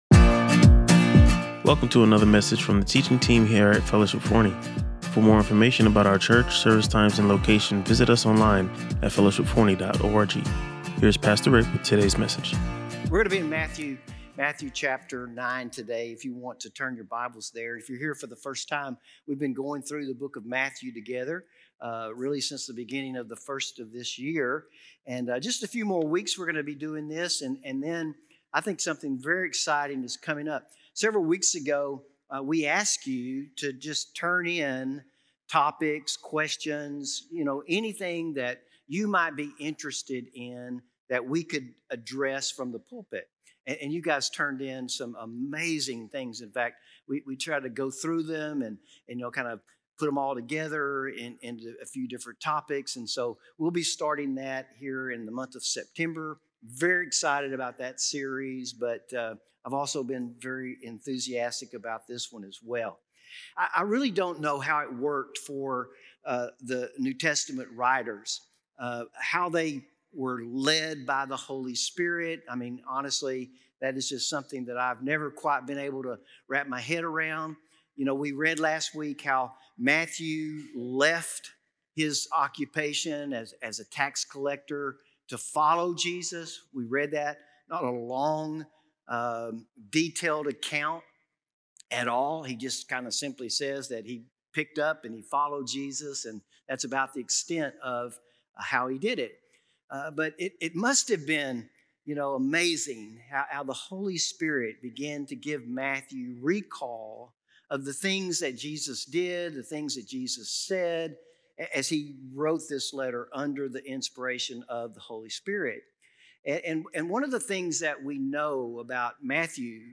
He challenged the congregation to consider what kind of world we would live in “according to our faith” and encouraged everyone to share the good news of what God has done in their lives.